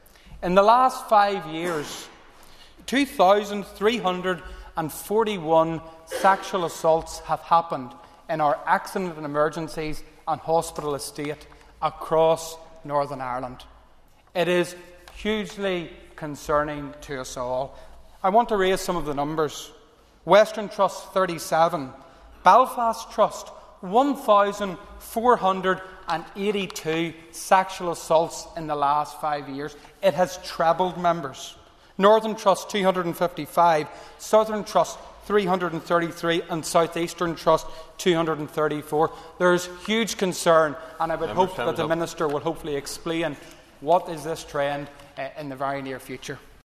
Mr. Buckley raised the issue in Stormont earlier this week: